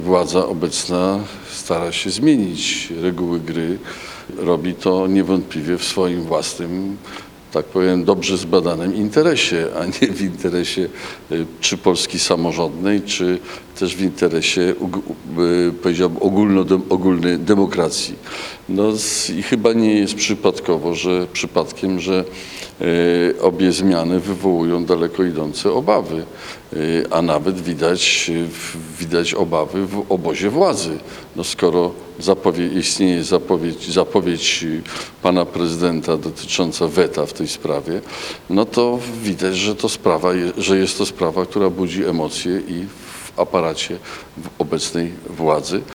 Mieszkańcy Gołdapi i okolic wzięli udział w sobotnim (11.08) spotkaniu z Bronisławem Komorowskim.
W odniesieniu do ostatnich informacji, że kandydatem Platformy Obywatelskiej w najbliższych wyborach prezydenckich będzie Donald Tusk, zapytaliśmy byłego prezydenta, czy nie chciałby zrewanżować się Andrzejowi Dudzie za wybory w 2015 roku.